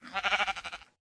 sheep1.ogg